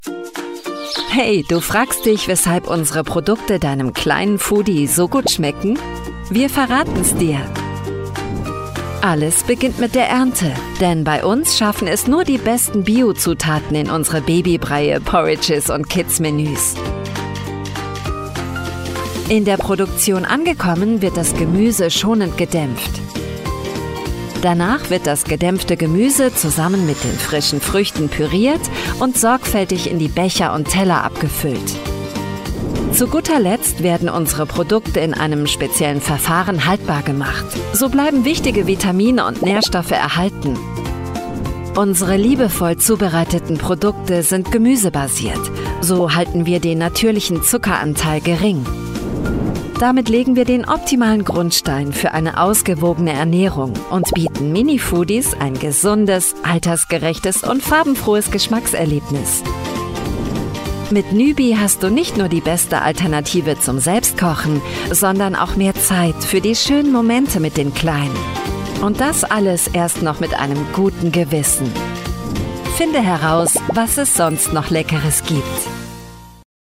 Native voices